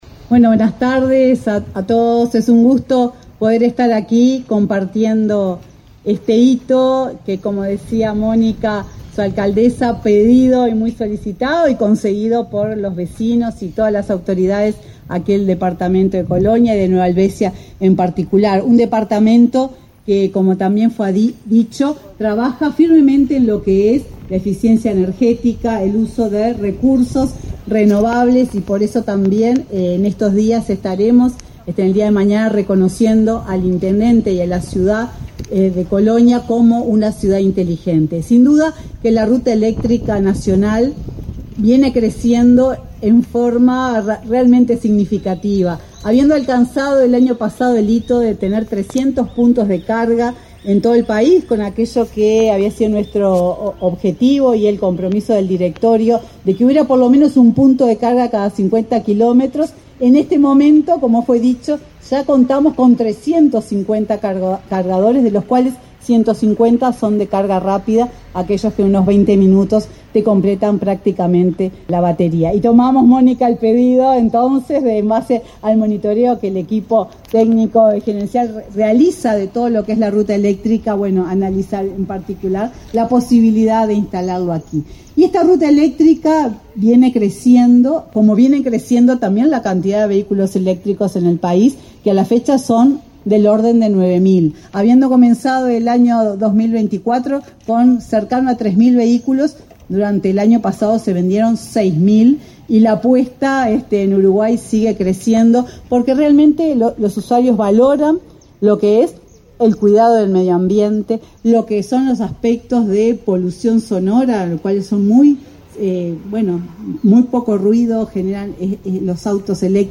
Palabras de la presidenta de UTE, Silvia Emaldi